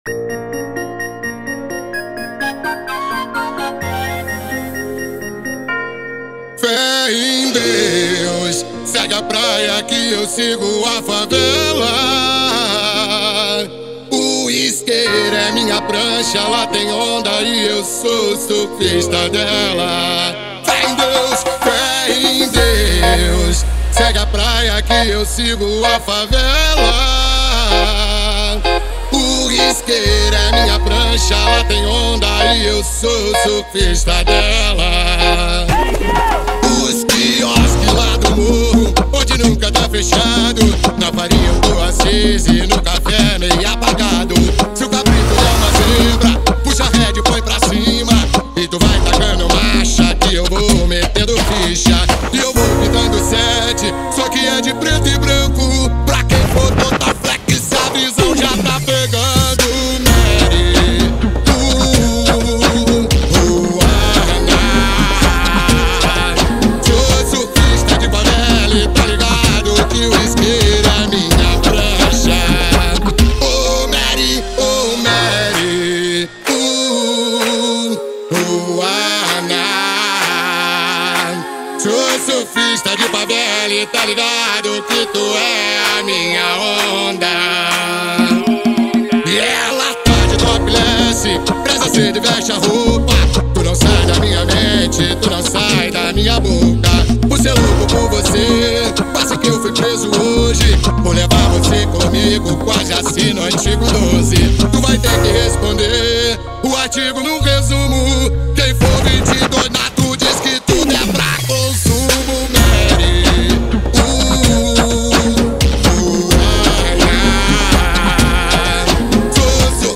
2024-11-05 21:28:44 Gênero: Funk Views